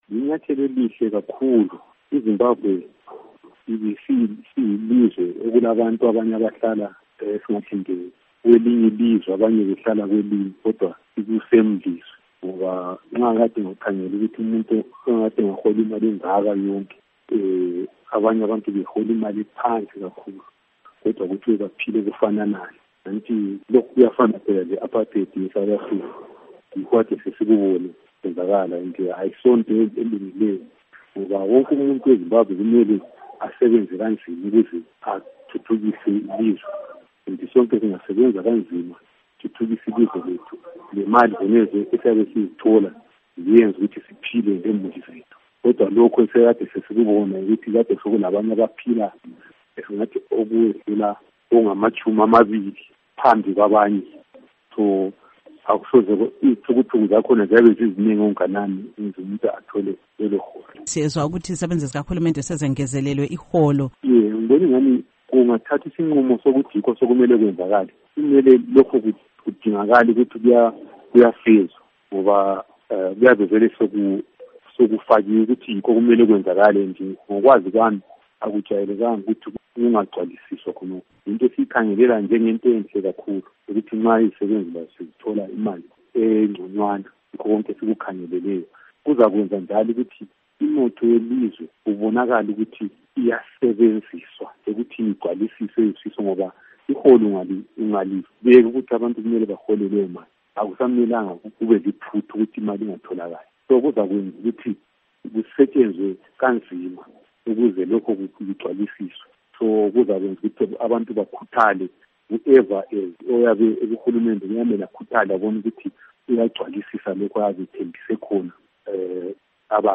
Ingxoxo LoAlderman Matson Hlalo